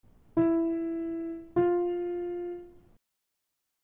المسافات الصوتية الثنائية
استمع إلى المسافات الثنائية التالية ثم حدد إن كانت صاعدة أو نازلة